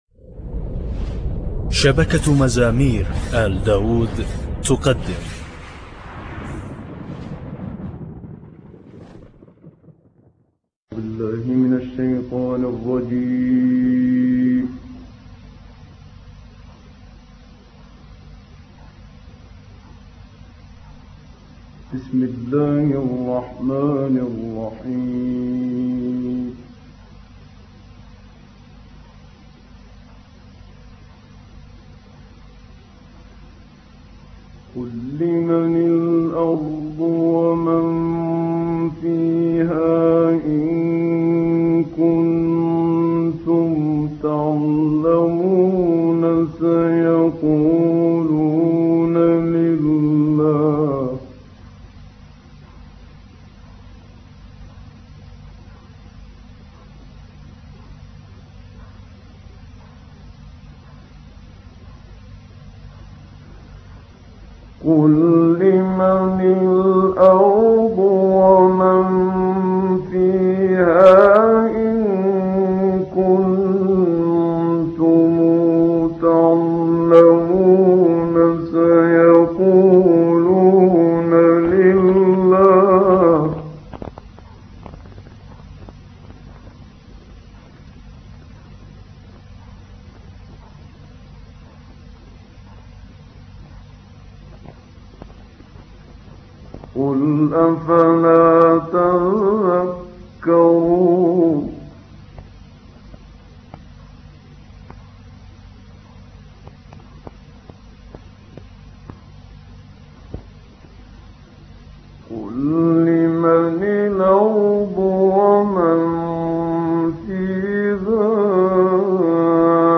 تلاوات ستوديو فئة 25-30 دقيقة - خمسينات للشيخ محمد صديق المنشاوي